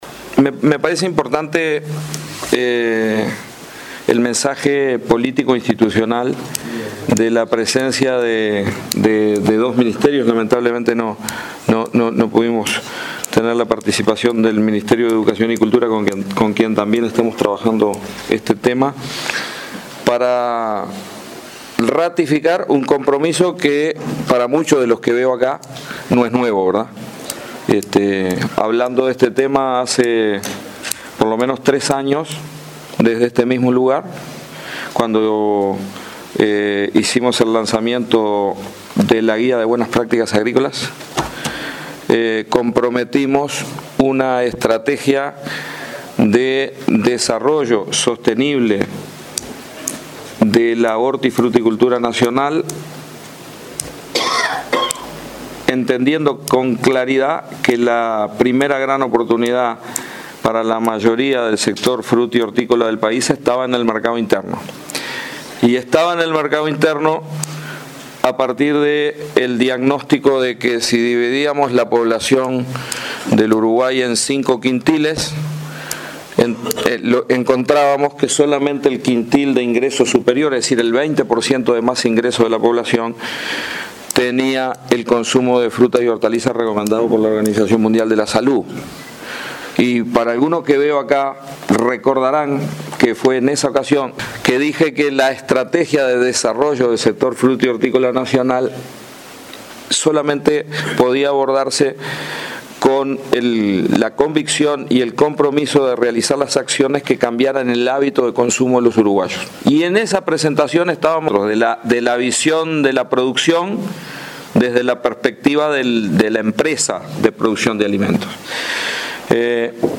“Todas las inversiones que hacemos para mejorar la alimentación de la generación de hoy serán ahorros de salud futura”, sostuvo durante la presentación de nuevos cultivares hortifrutícolas.